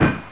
impact.mp3